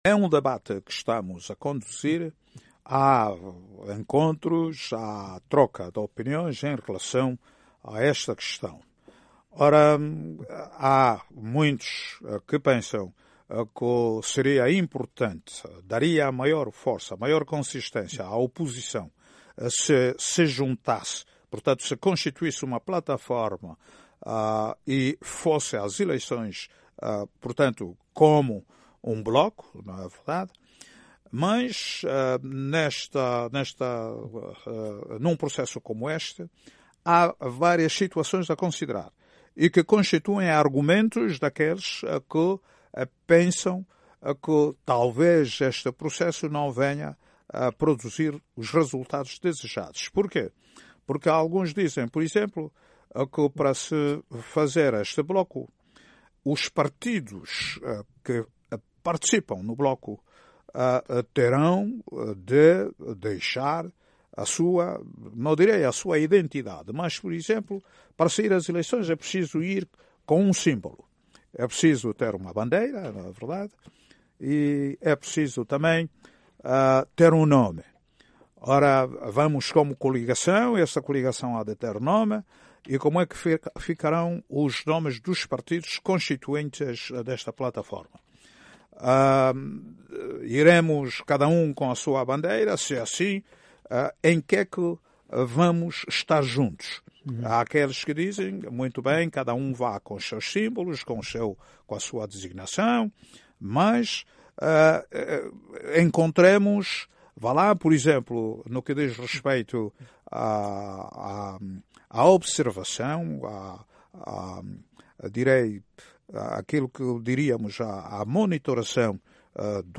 Numa entrevista à Voz da América, Samakuva afirmou que há neste momento “um debate” sobre o tipo de cooperação que deve ou não existir entre os partidos da oposição nas próximas eleições.